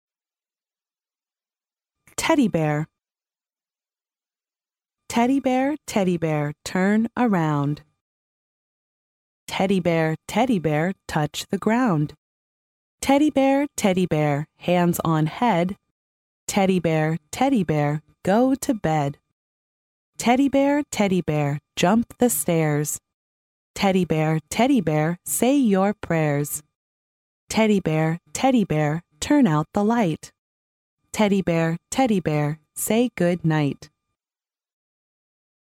幼儿英语童谣朗读 第6期:泰迪熊 听力文件下载—在线英语听力室